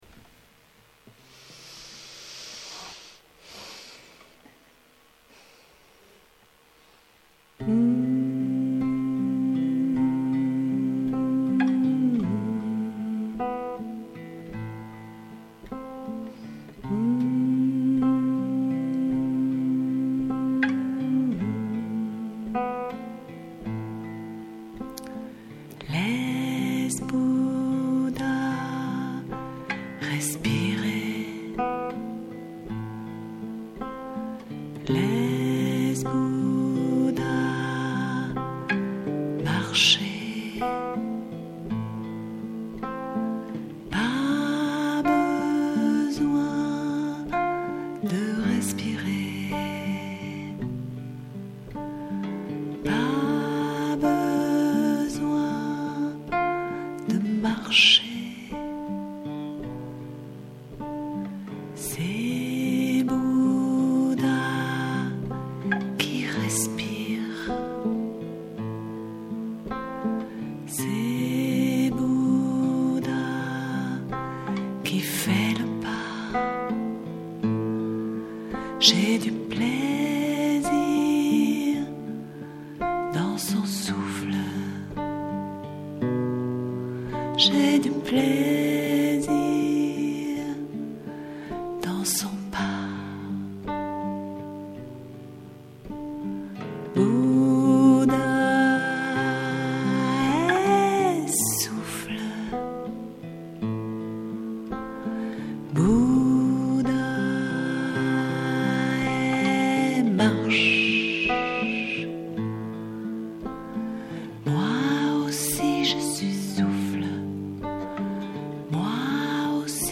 Une chanson diffusée lors de la pratique du samedi 7 septembre 2019 au C.S.H. à Mouvaux.